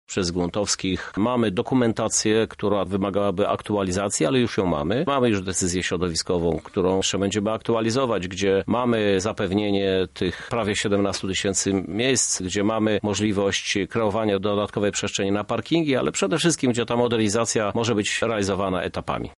stadion -dodaje Żuk.